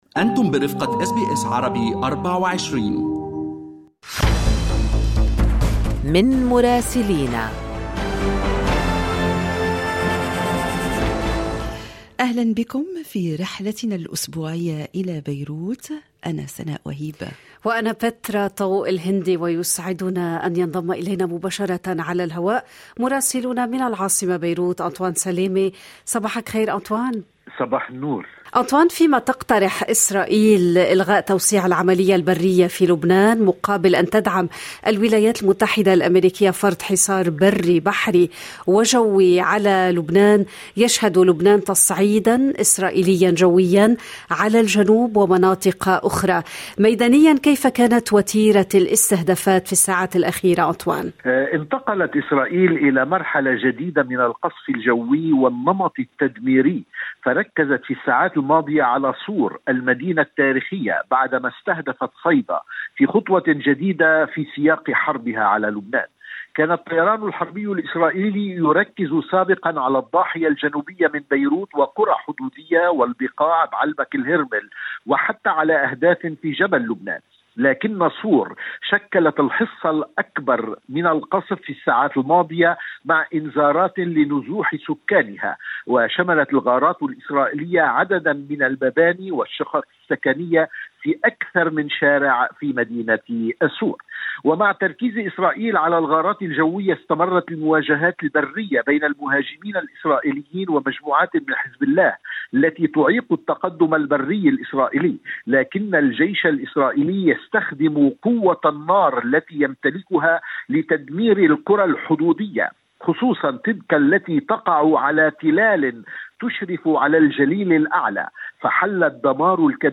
يمكنكم الاستماع إلى تقرير مراسلنا في العاصمة بيروت بالضغط على التسجيل الصوتي أعلاه.